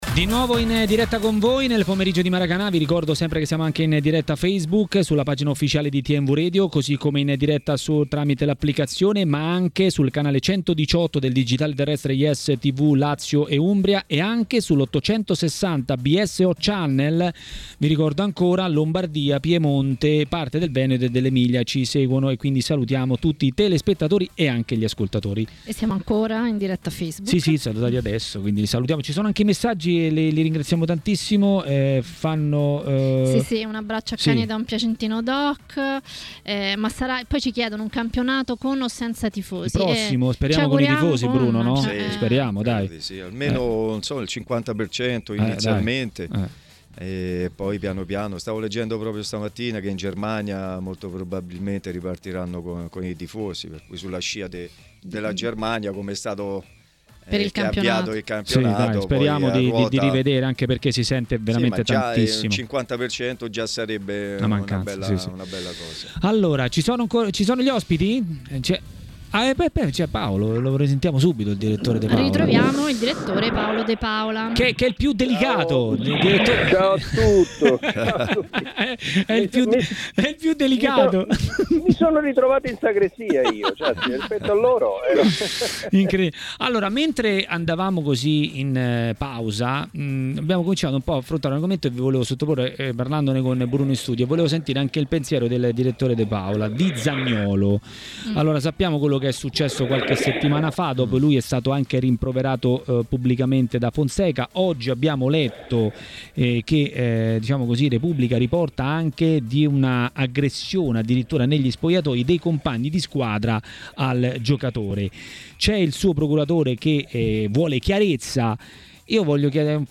A TMW Radio, durante Maracanà, per parlare dei temi del momento è intervenuto l'ex bomber Marco Ferrante.